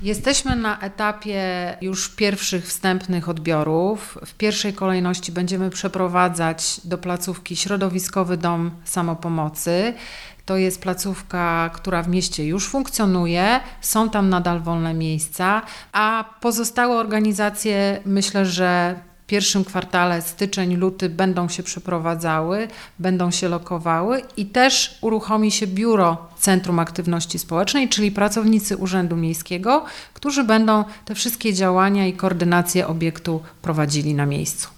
– Chcemy, aby ten budynek tętnił życiem – powiedziała Natalia Walewska – Wojciechowska, wiceprezydent Nowej Soli: